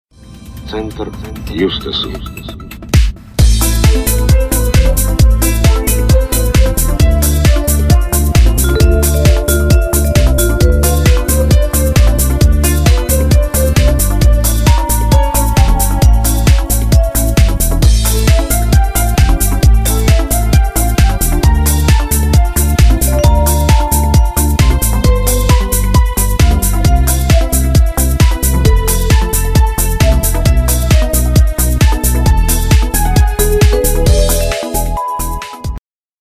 • Качество: 256, Stereo
Ремикс на тему из фильма